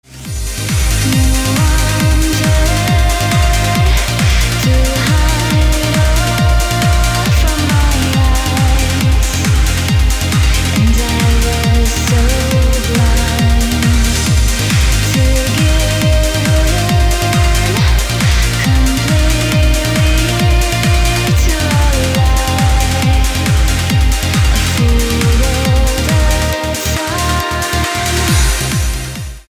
• Качество: 320, Stereo
dance
Electronic
электронная музыка
спокойные
club
красивый женский голос
Trance
vocal trance